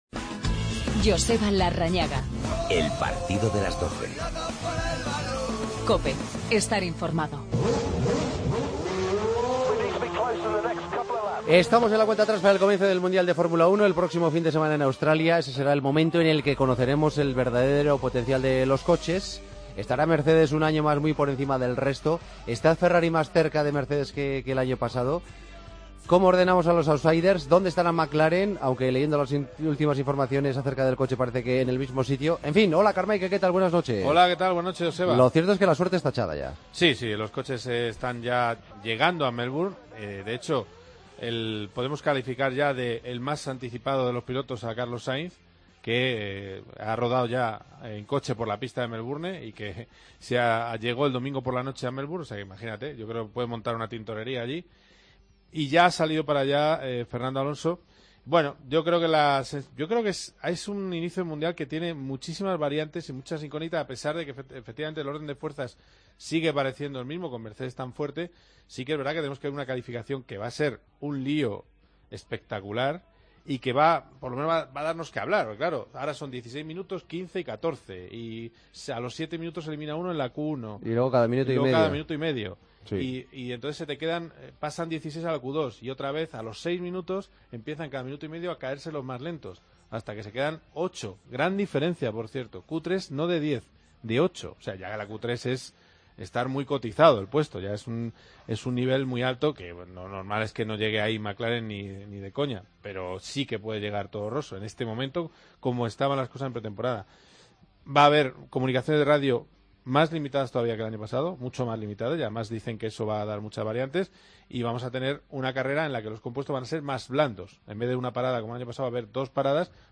Entrevista a Roberto Merhi.